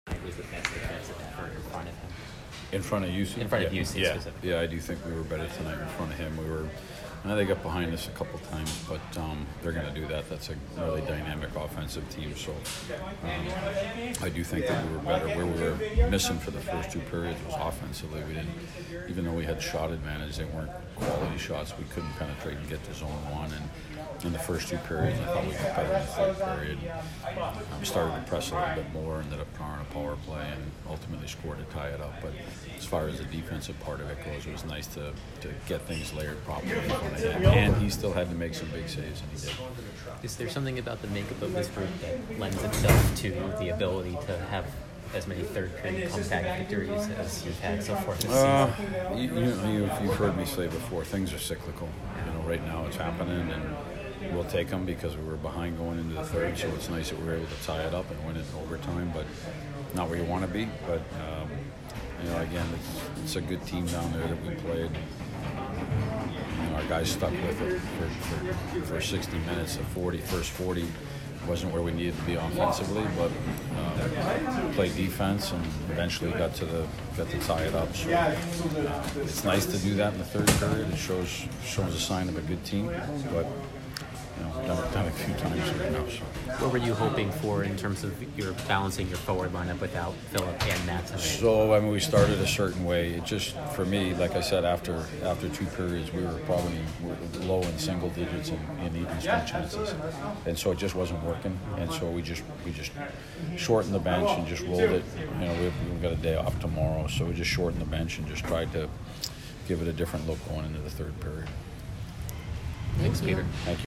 Peter Laviolette post-game 10/26